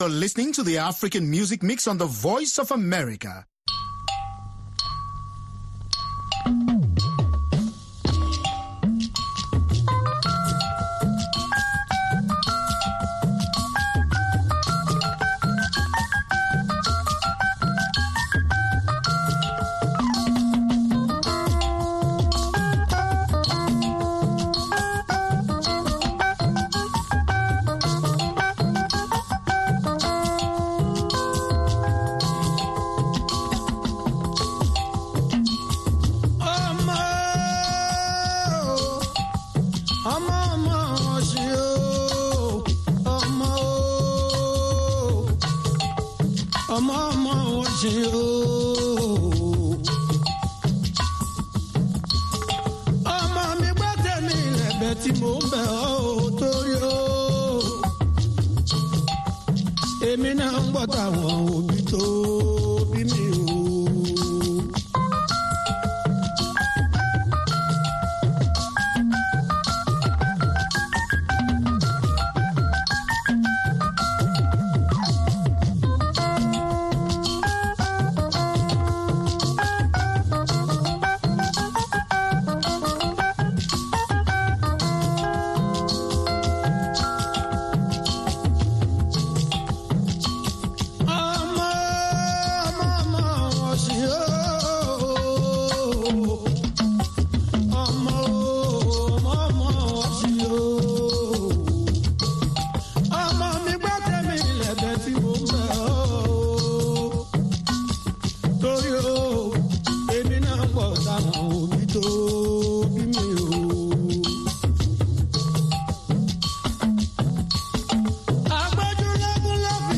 African Beat showcases the latest and the greatest of contemporary African music and conversation.